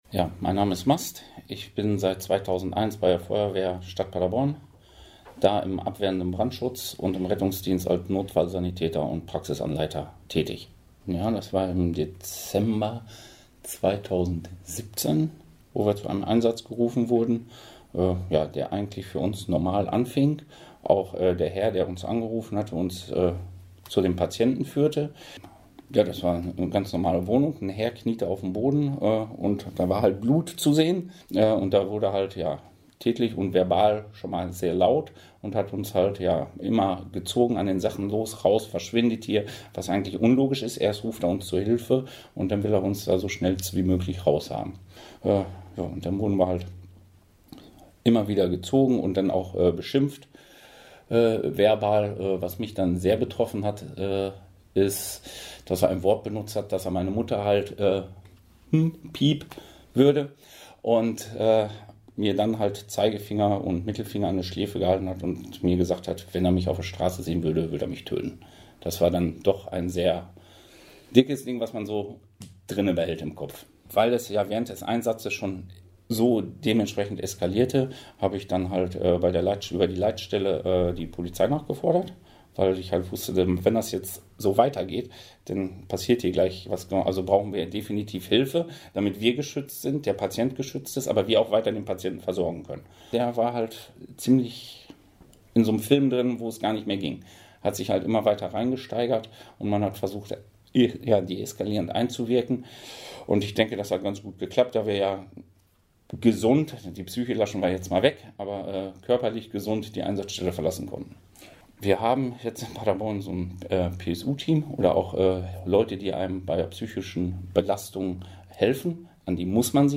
Wir haben Interviews mit den Betroffenen geführt.